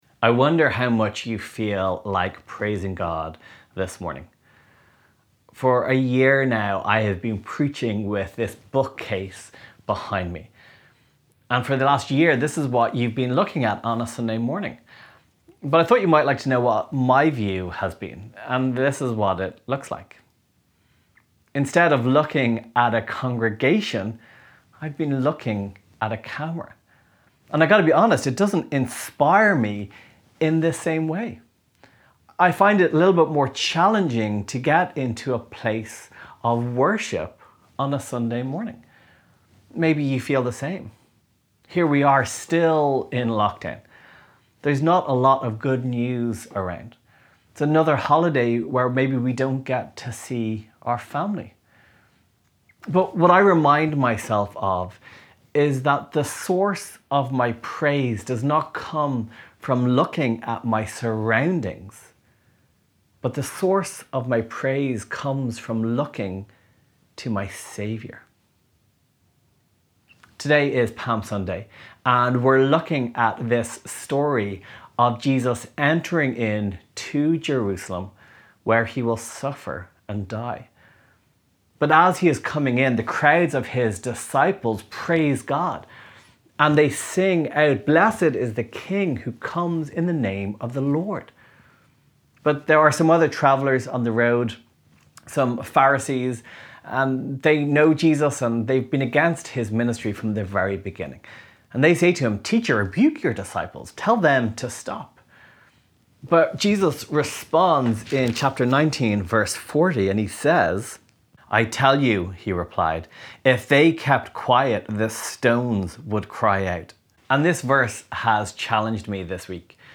Downloads March/Easter Reading Plan Download Sermon MP3 Share this: Share on X (Opens in new window) X Share on Facebook (Opens in new window) Facebook Like Loading...